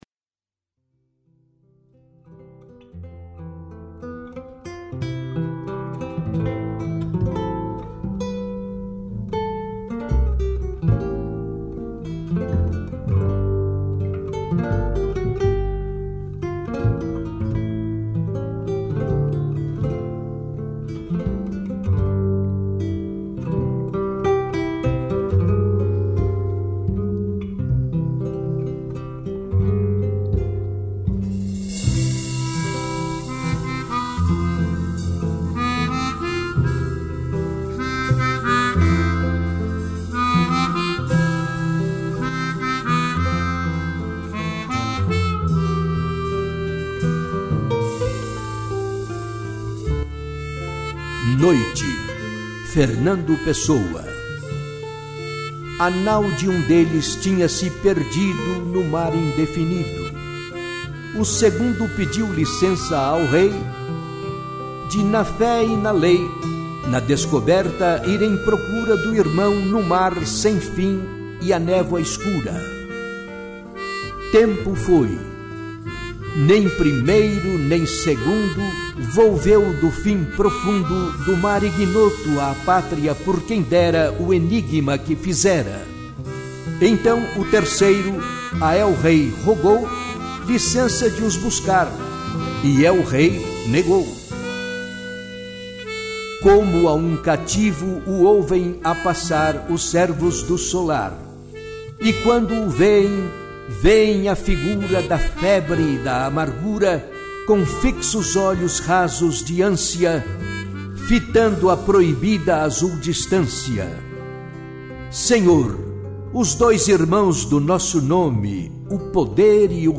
Noite - declamação